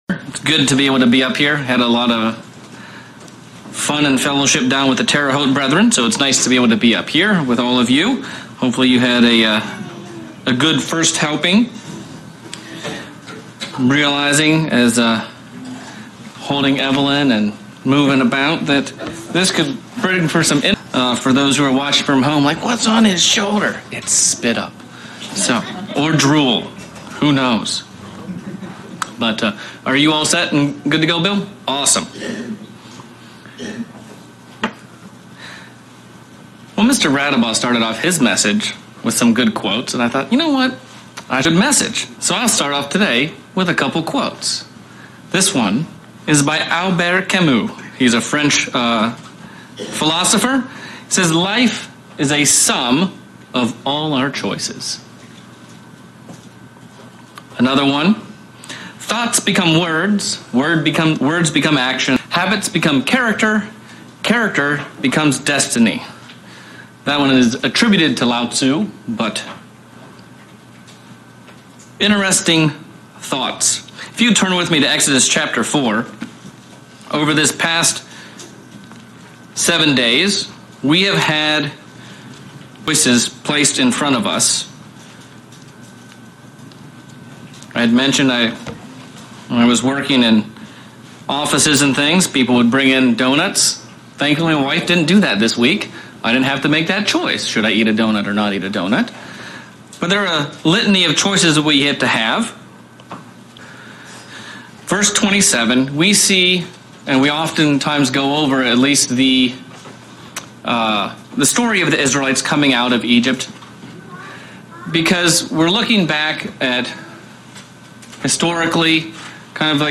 Last Day of Unleavened Bread sermon focusing on the week we have just worked hard and the choices we make going forward from these days show God that we are the Bride made ready for his Son.